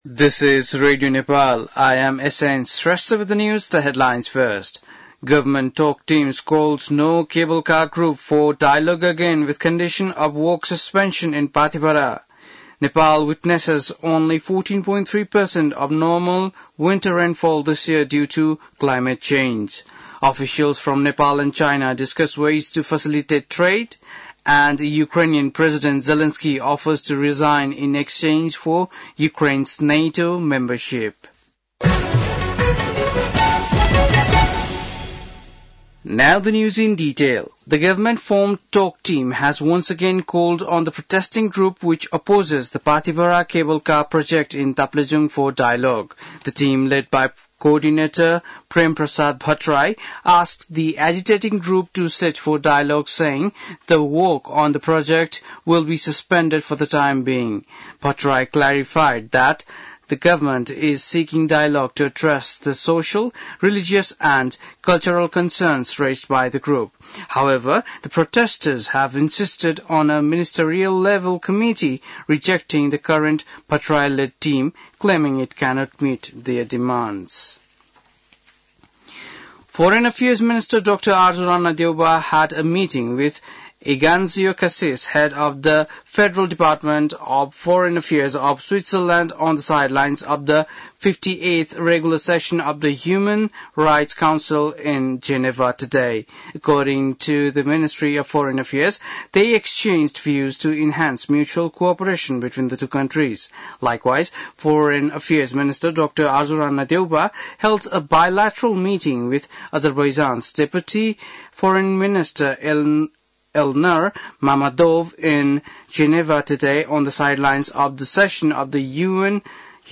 बेलुकी ८ बजेको अङ्ग्रेजी समाचार : १३ फागुन , २०८१
8-pm-english-news-11-12.mp3